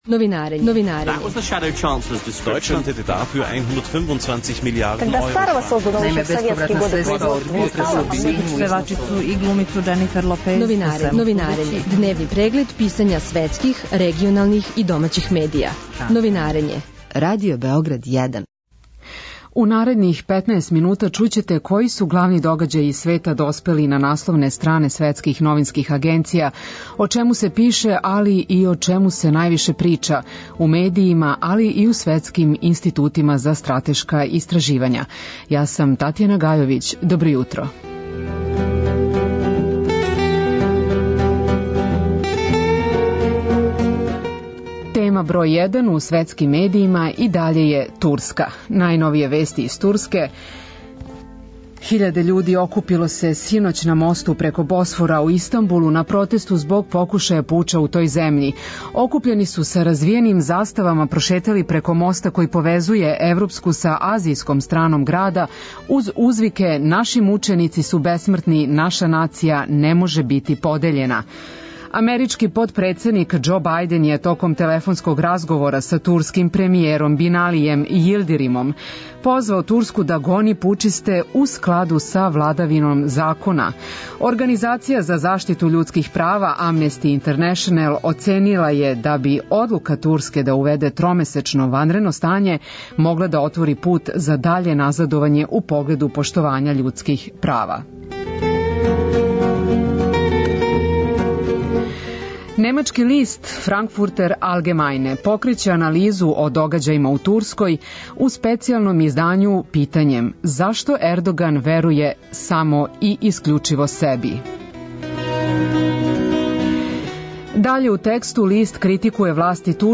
Директор америчког Форума за Блиски исток Денијел Пајпс говори за Радио Београд о могућој трансформацији турског друштва пошто је Реџеп Тајип Ердоган прогласио ванредно стање на три месеца, а на месец и по дана укинуо Европску конвенцију о људским правима.